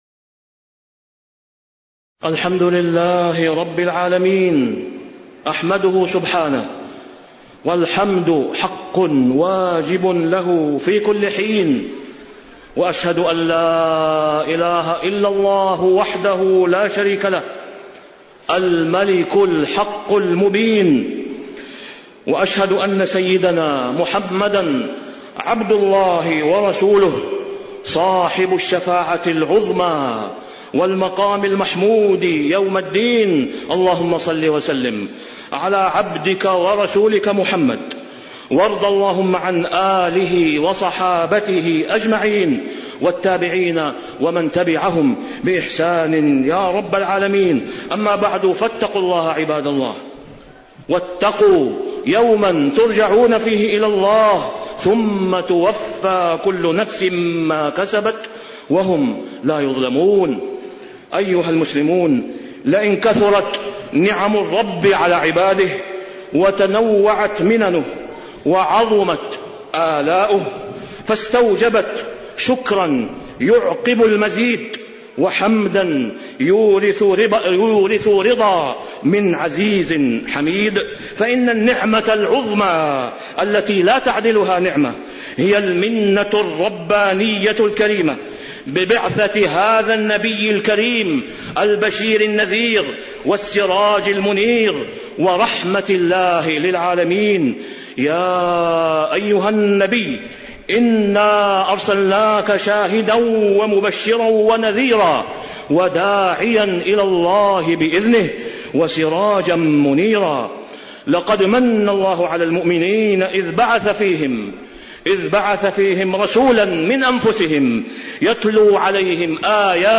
تاريخ النشر ٩ ربيع الأول ١٤٣٥ هـ المكان: المسجد الحرام الشيخ: فضيلة الشيخ د. أسامة بن عبدالله خياط فضيلة الشيخ د. أسامة بن عبدالله خياط حقوق النبي صلى الله عليه وسلم على أمته